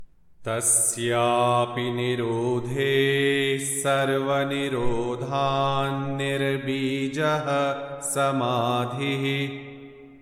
Yoga Sutra 1.51 | Tasyāpi nirodhe sarva-nirodhān nir-bījaḥ...| Chant Sutra 1.51